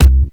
Kick (Lift Yourself).wav